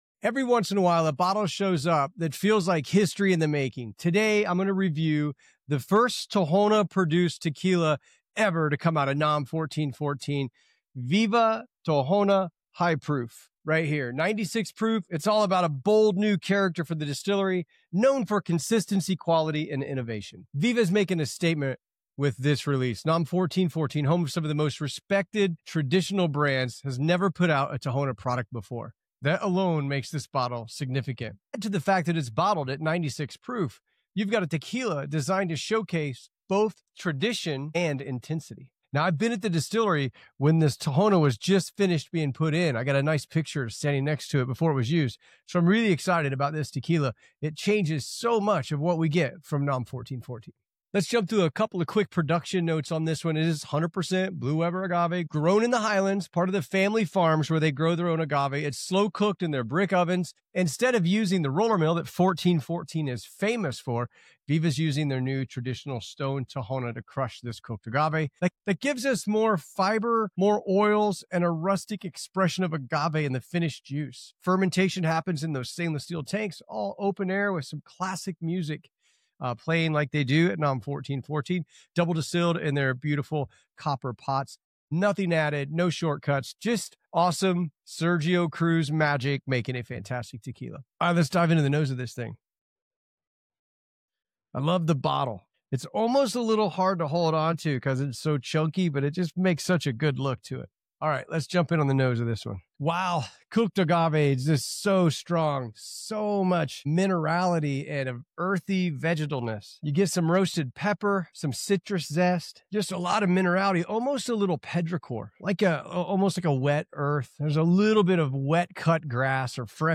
Don’t forget to like, subscribe, and share if you enjoy tequila reviews.